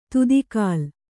♪ tudi kāl